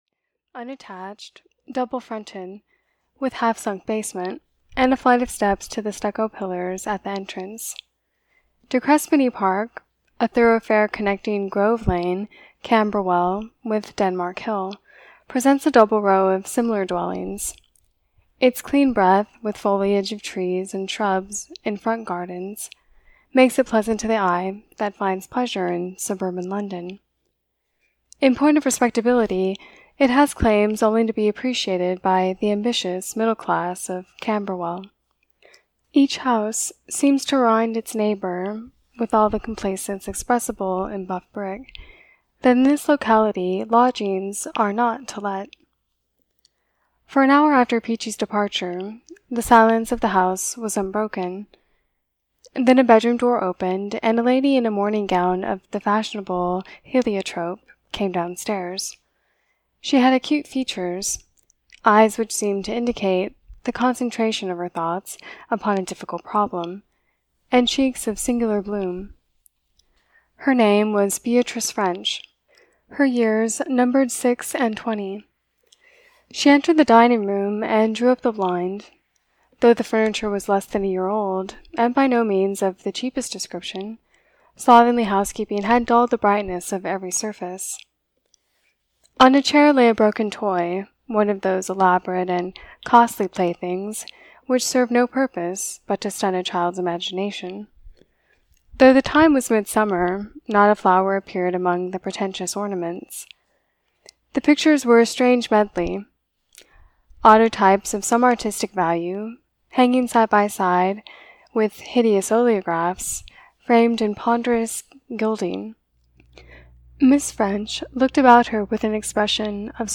In the Year of the Jubilee (EN) audiokniha
Ukázka z knihy